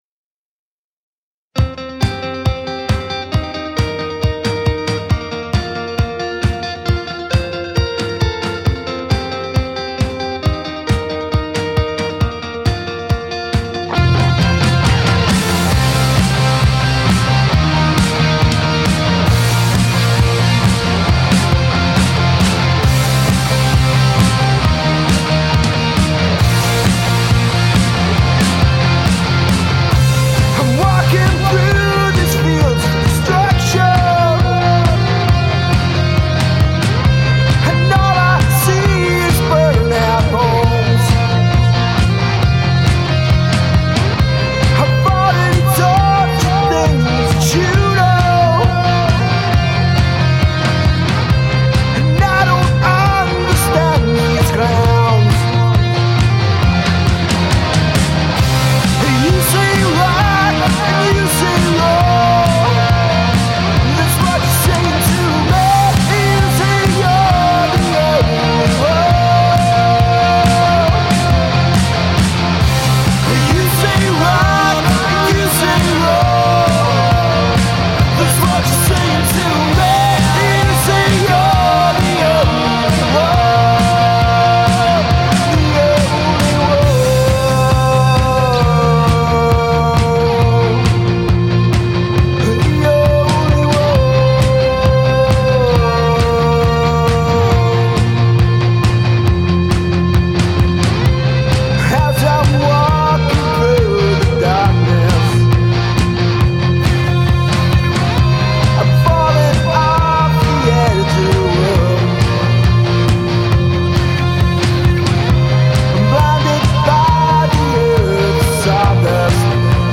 3 piece guitar based band
Guitar / Vocals